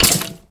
Minecraft Version Minecraft Version 1.21.4 Latest Release | Latest Snapshot 1.21.4 / assets / minecraft / sounds / mob / skeleton / hurt2.ogg Compare With Compare With Latest Release | Latest Snapshot
hurt2.ogg